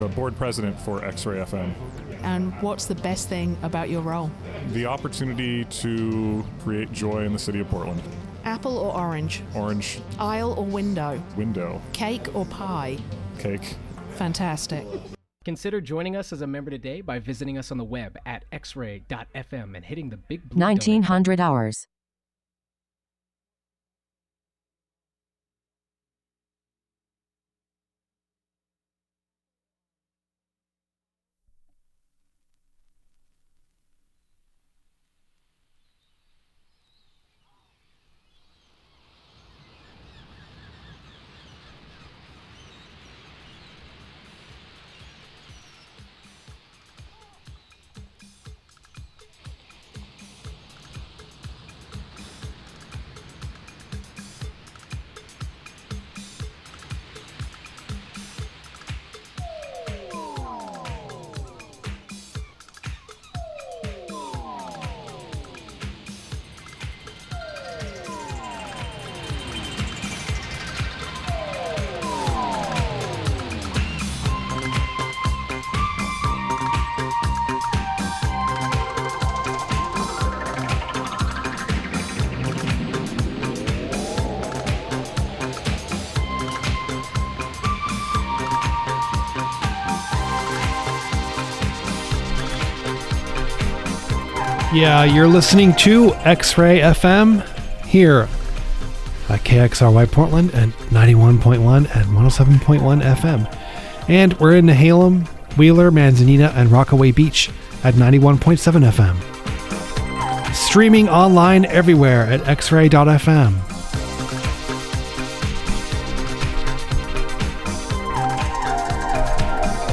SAVAGE BEAT is garage, surf, proto-punk and a little more of the like plus some out of character stuff from time to time! Weirdo screamers and trashy creepers.